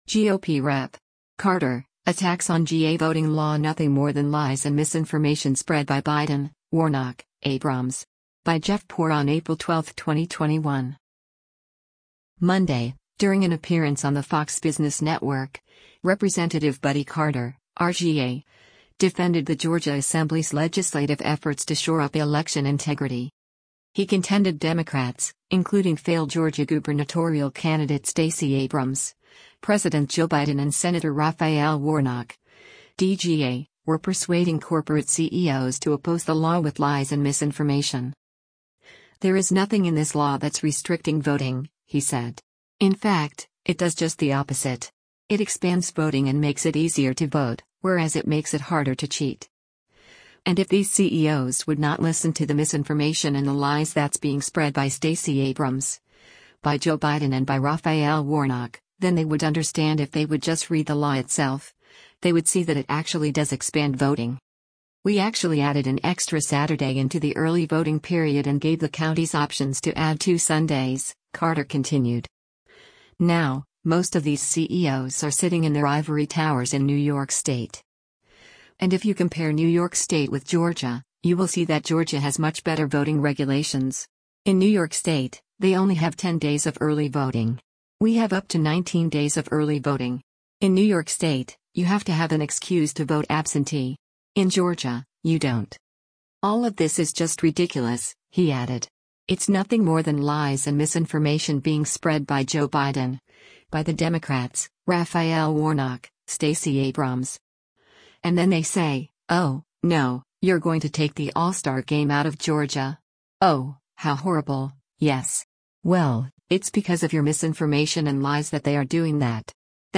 Monday, during an appearance on the Fox Business Network, Rep. Buddy Carter (R-GA) defended the Georgia Assembly’s legislative efforts to shore up election integrity.